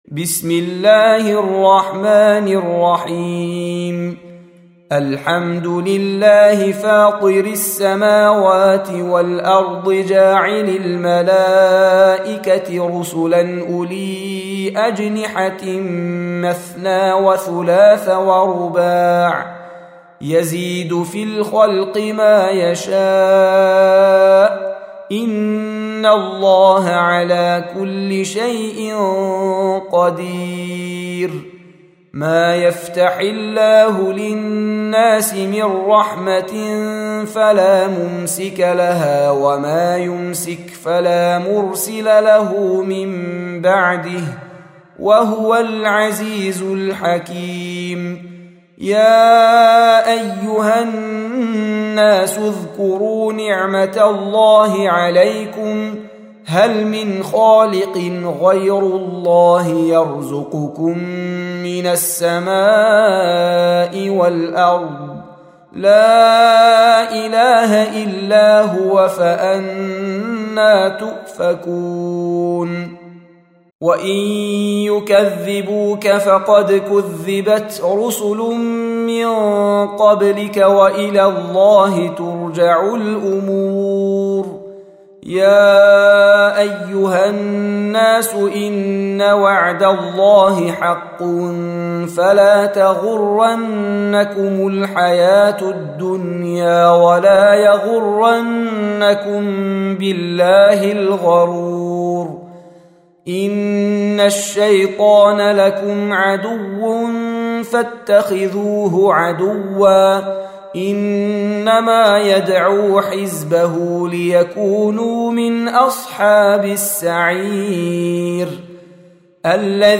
Surah Repeating تكرار السورة Download Surah حمّل السورة Reciting Murattalah Audio for 35. Surah F�tir or Al�Mal�'ikah سورة فاطر N.B *Surah Includes Al-Basmalah Reciters Sequents تتابع التلاوات Reciters Repeats تكرار التلاوات